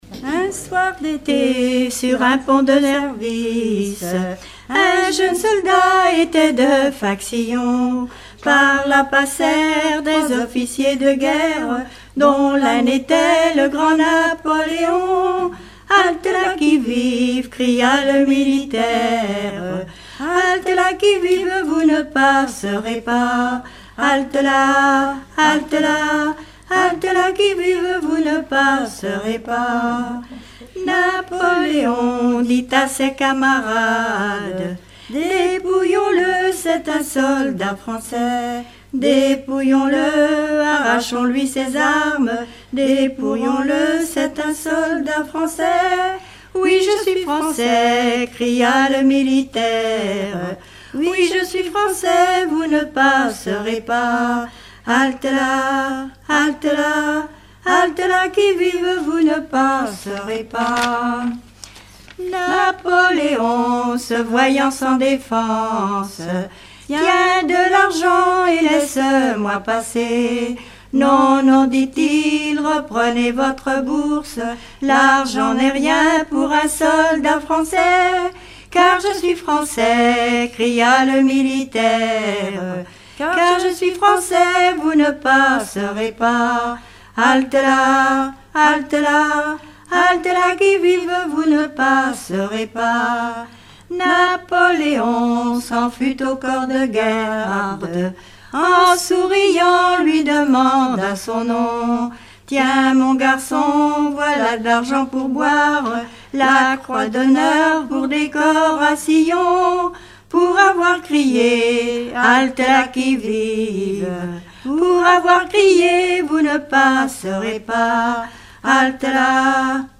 Regroupement de chanteurs du canton
Pièce musicale inédite